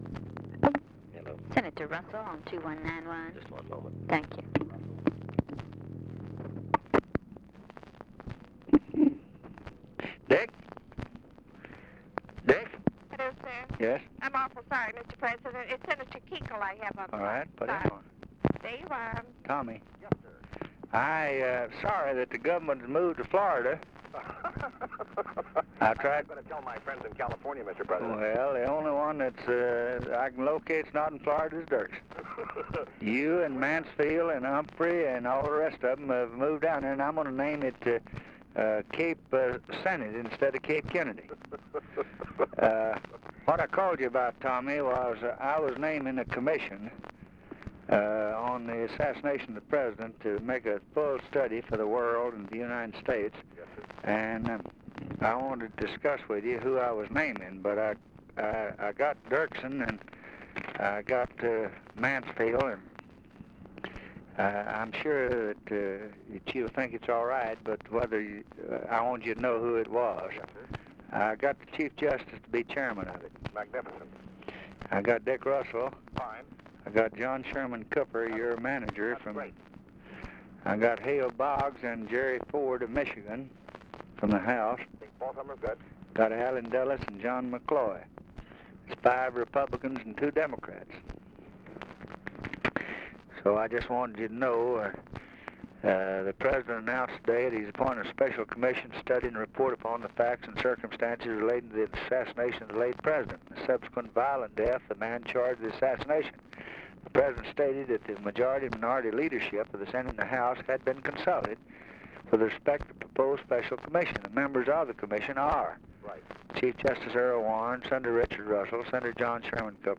Conversation with THOMAS KUCHEL, November 30, 1963
Secret White House Tapes | Lyndon B. Johnson Presidency Conversation with THOMAS KUCHEL, November 30, 1963 Rewind 10 seconds Play/Pause Fast-forward 10 seconds 0:00 Download audio Previous Conversation with WILLIAM MCC.